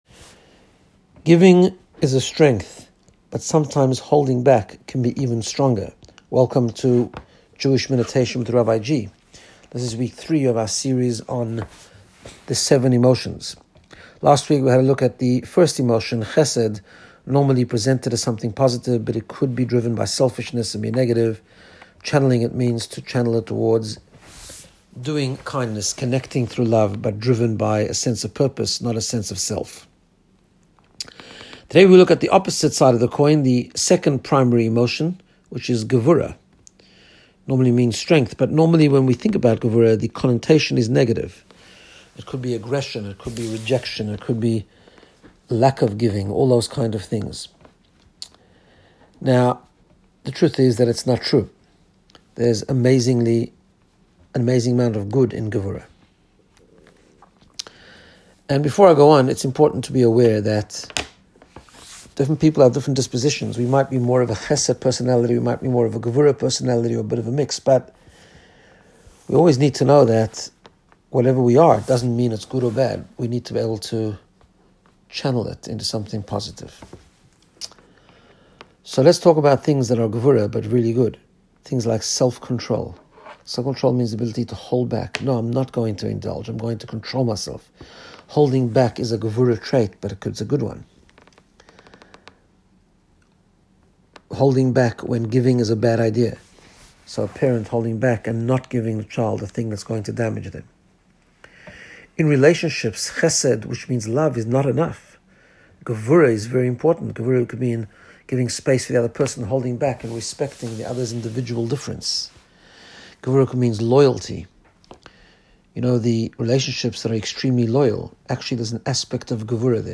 Guided Meditation to get you in the sapce of Personal Growth and Wellbeing.